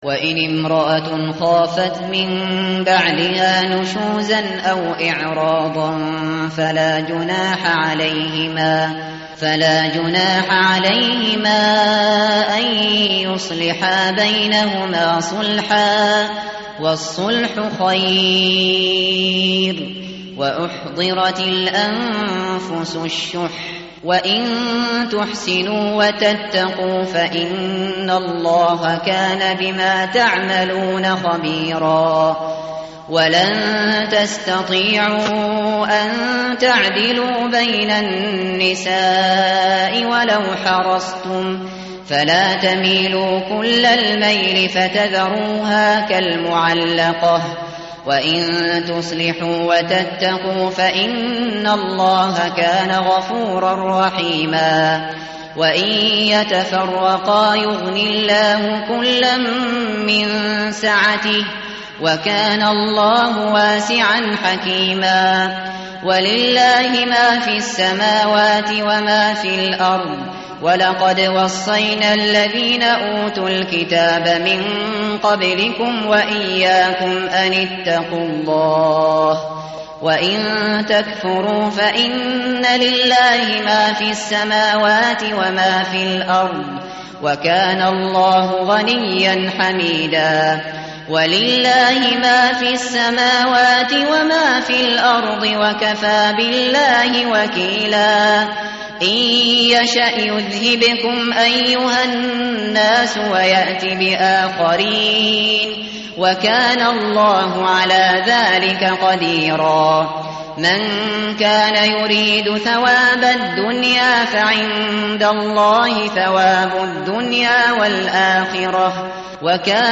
متن قرآن همراه باتلاوت قرآن و ترجمه
tartil_shateri_page_099.mp3